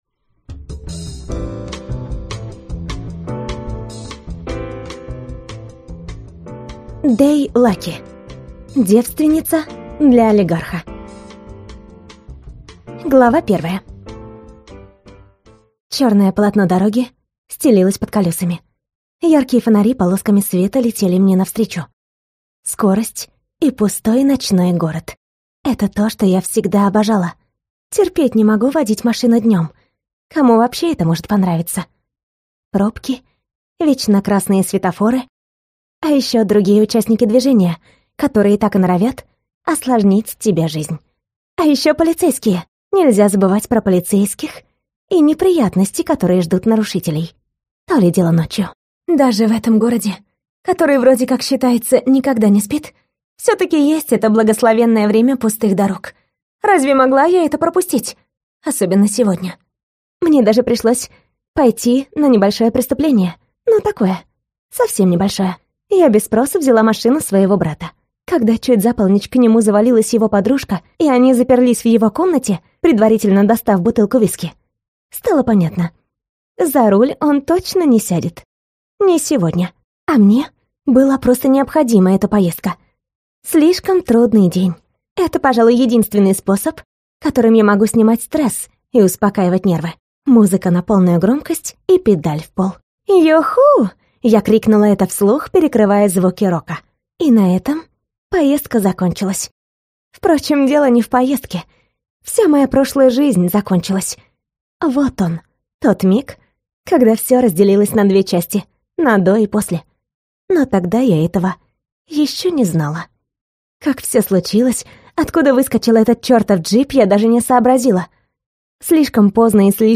Аудиокнига Девственница для олигарха | Библиотека аудиокниг